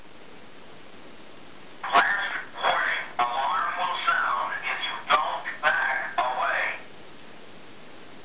However, he also knows a couple of phrases: (click to hear Wolfgang speak)